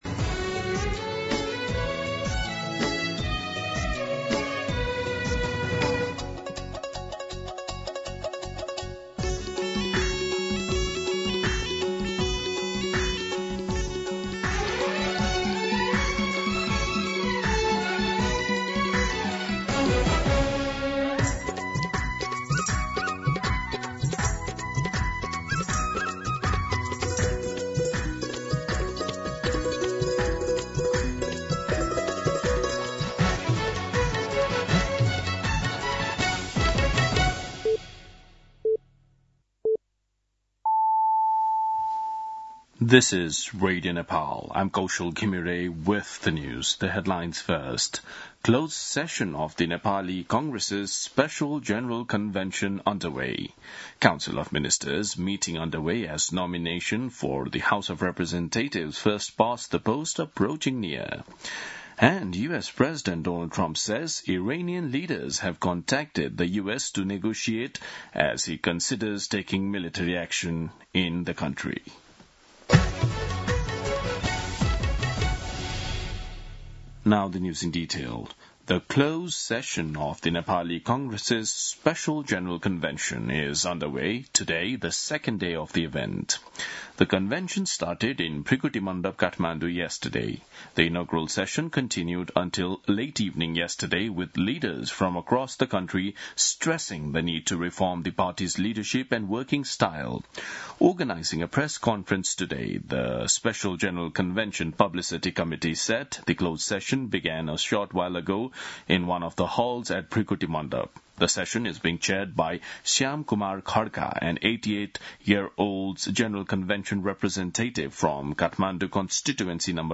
दिउँसो २ बजेको अङ्ग्रेजी समाचार : २८ पुष , २०८२